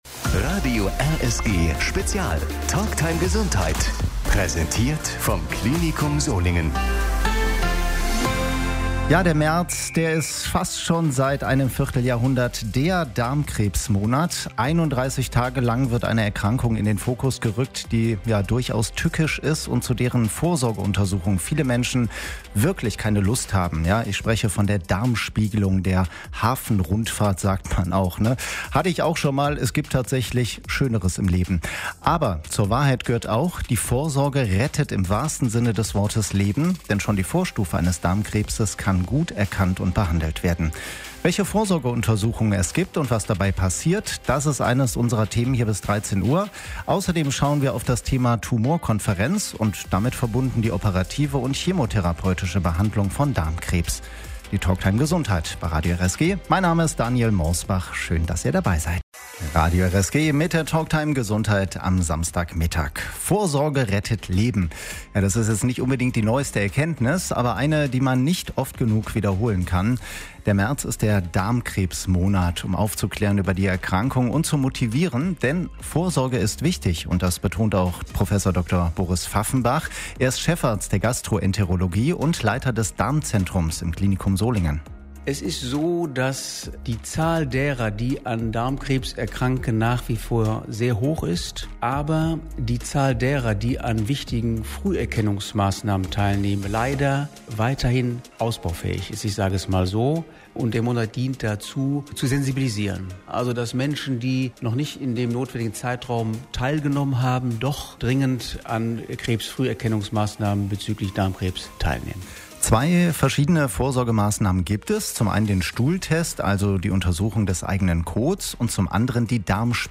Die Talktime Gesundheit lief am Samstag, 22. März 2025, von 12 bis 13 Uhr bei Radio RSG.